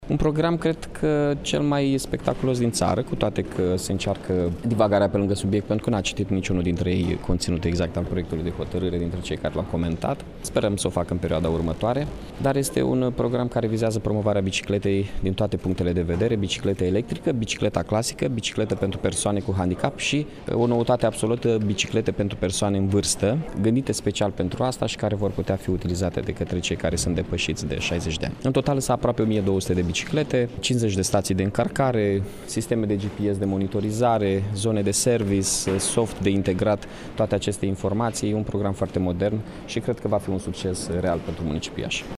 Primarul Mihai Chirica a spus că cel mai disputat punct de pe ordinea de zi a fost cel legat de achiziționat a aproximativ 1.200 de biciclete prin proiectul Iași Velo City: